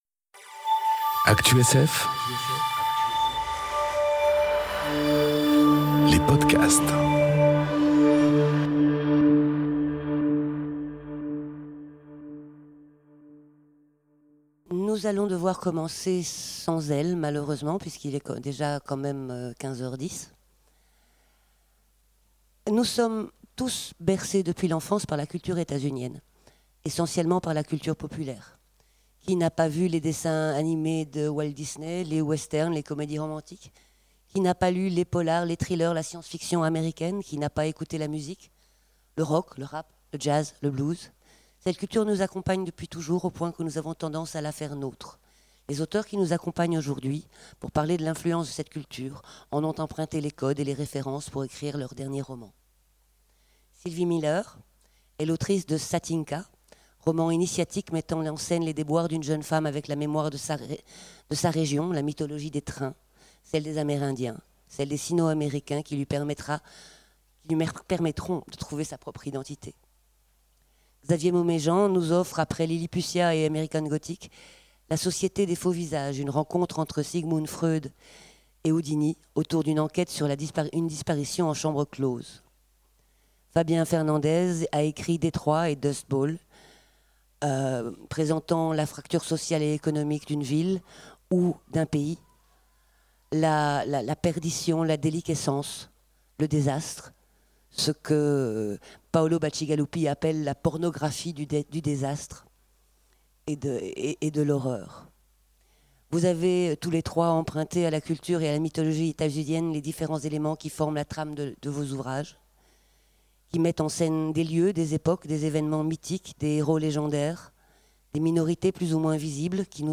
Conférence Mythologies nord-américaines... En quoi nous parlent-elles ? enregistrée aux Imaginales 2018